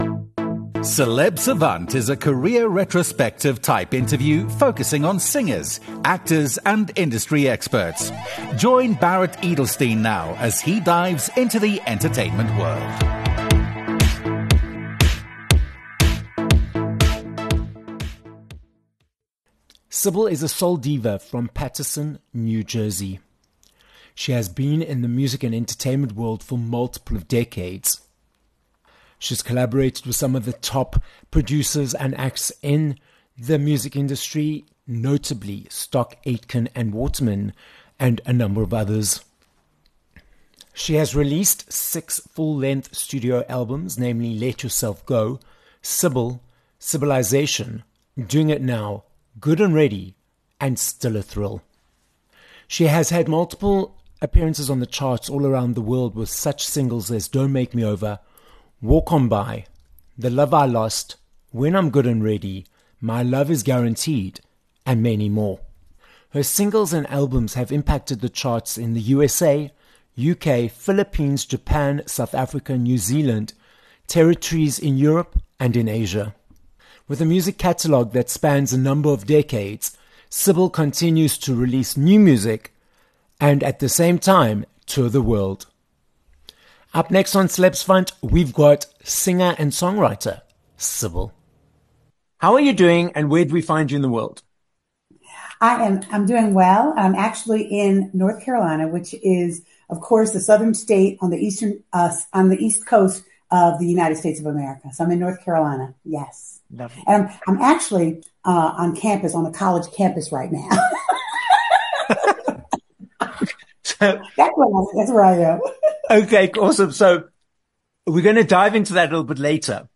29 Feb Interview with Sybil